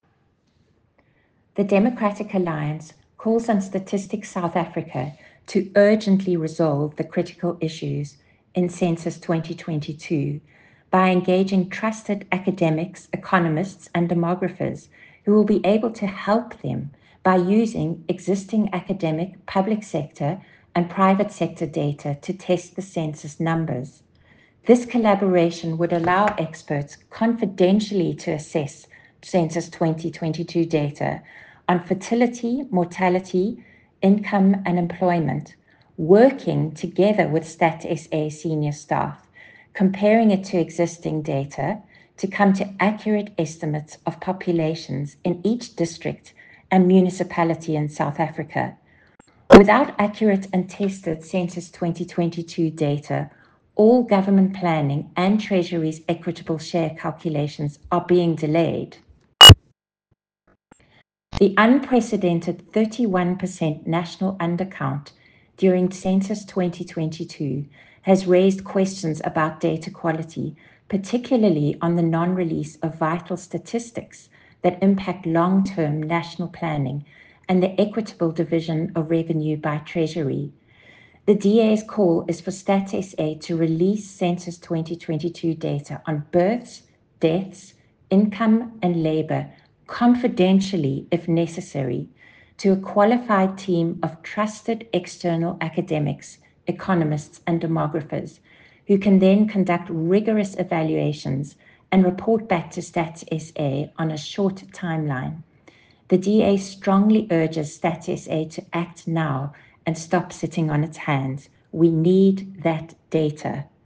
soundbite by Kate Christie MP.